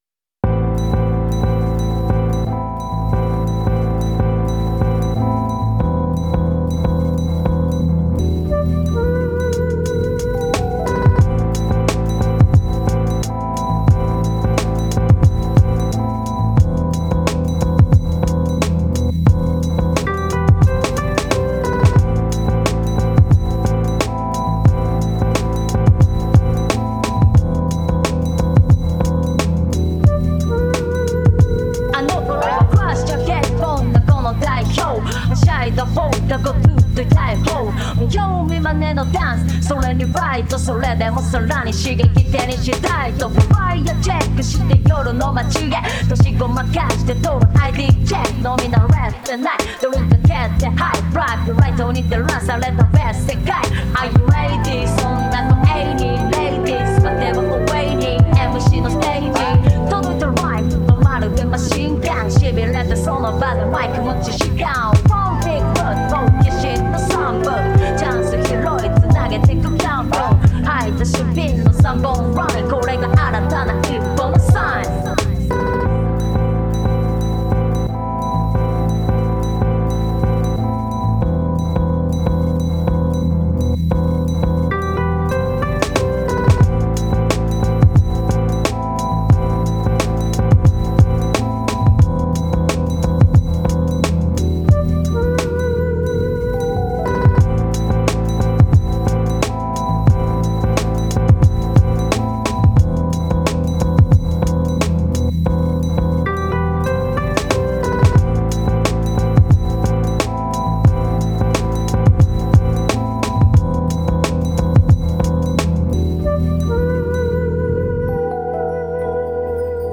Really enjoyed putting this jam together for the fun of it on my Digitakt.
too smoove :fire: beat
Also, neat to see the Japanese vocals in the mix.